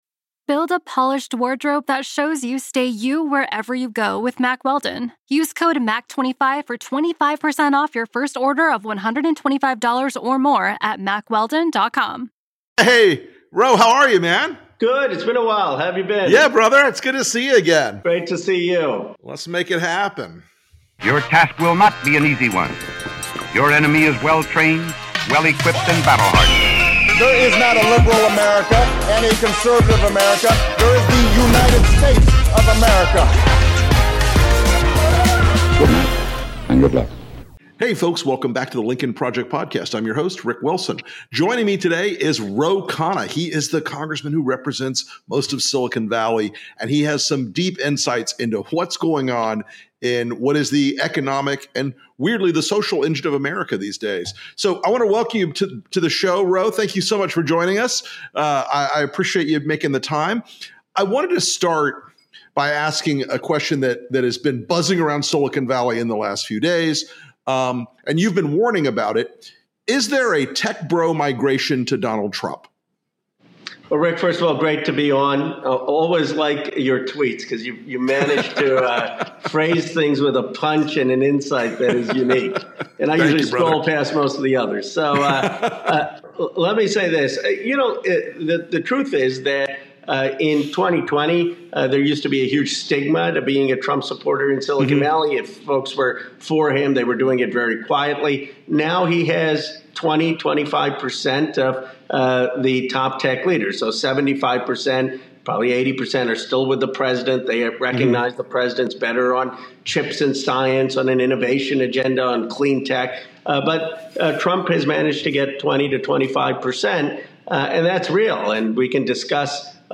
Rick Wilson interviews Congressman Ro Khanna, who represents Silicon Valley, on a range of critical topics. They discuss the tech industry’s support for Donald Trump, America’s and Congress’s readiness for artificial general intelligence (AGI), the necessity of antitrust regulation in Silicon Valley, the future of TikTok, and the impact of disinformation on politics.